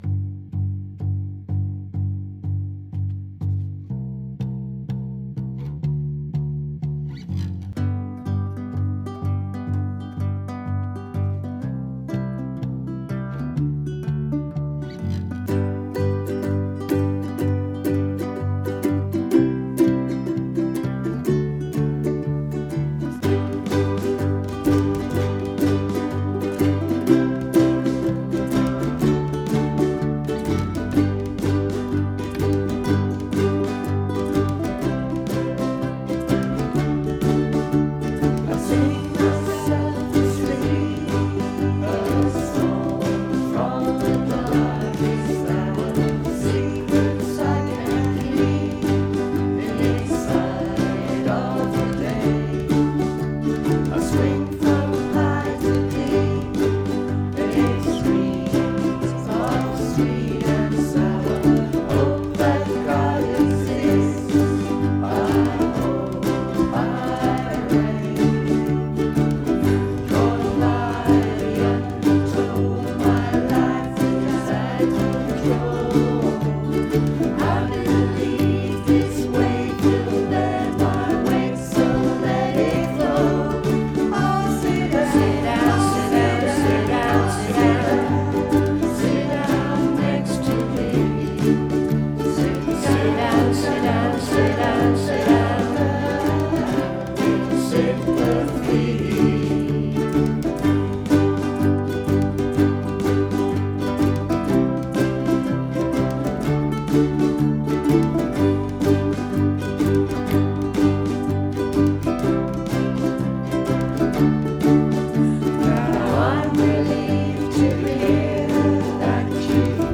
led by a great bass track
a guest appearance on drums